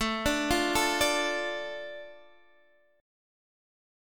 Asus4#5 chord